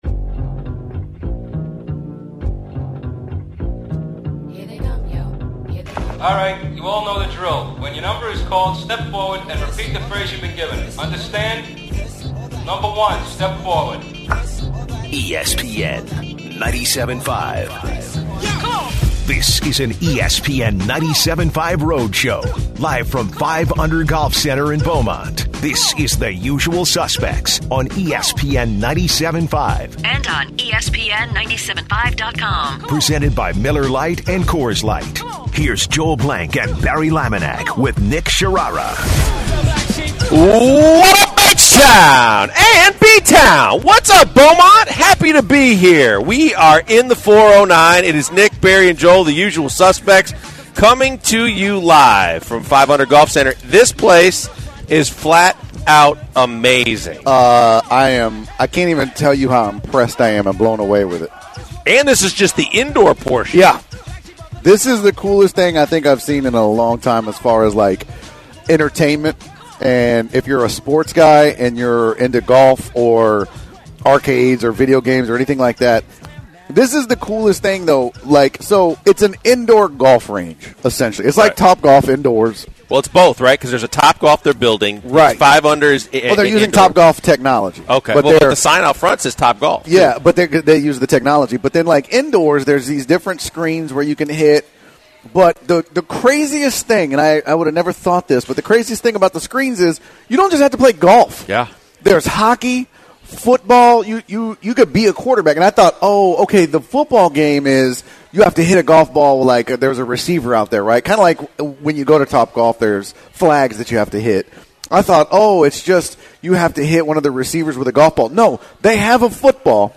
The guys are live from Beaumont today!